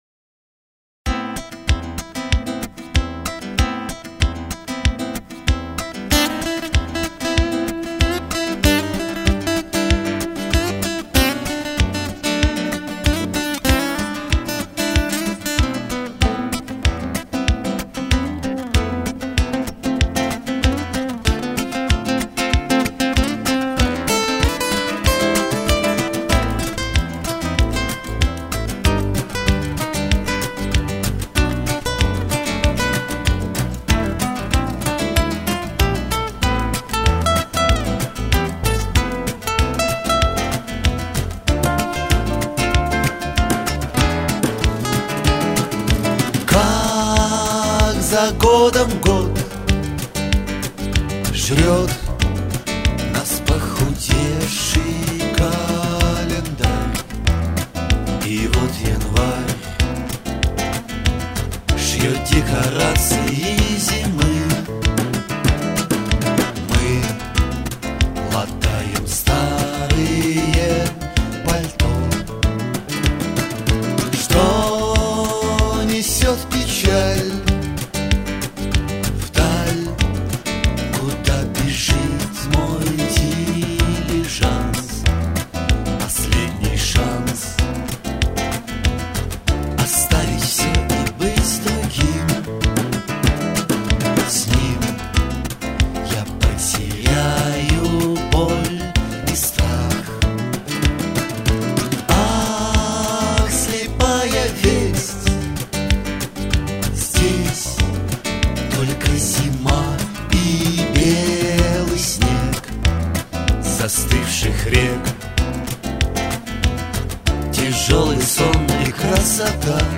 соло-гитара
бас-гитара